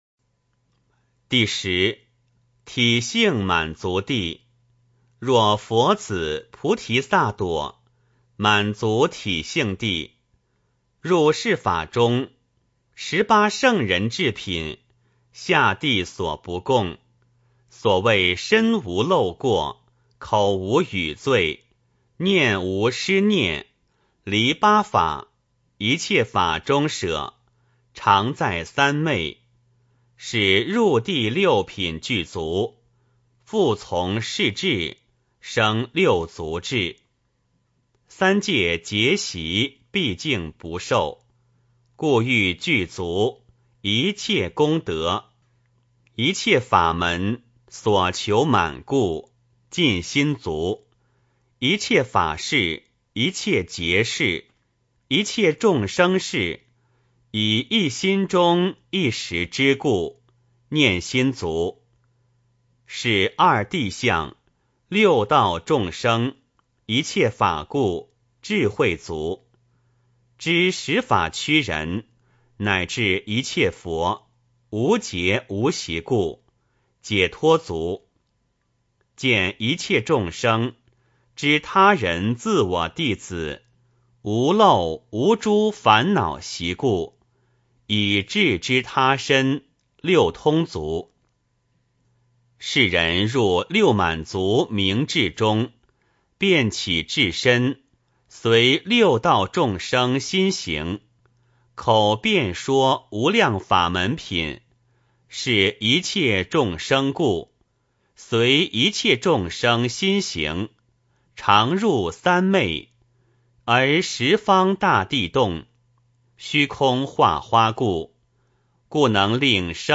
梵网经-十地-体性满足地 - 诵经 - 云佛论坛